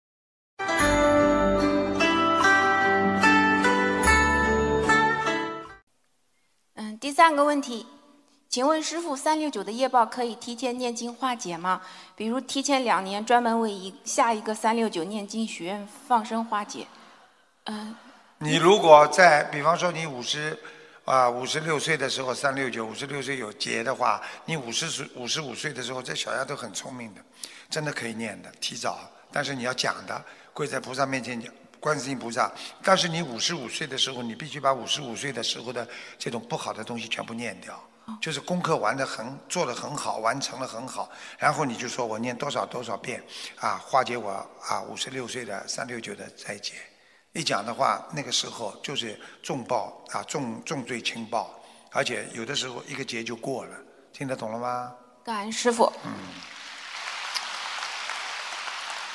音频：三六九的业报可以提前念经化解吗！2019年12月7日澳大利亚墨尔本世界佛友见面会提问